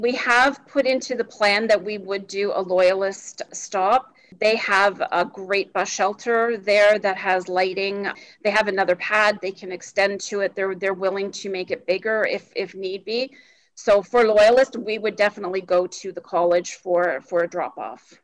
Quinte Access Transit provided an update on its ongoing rebranding efforts at Tuesday’s Quinte West Council meeting.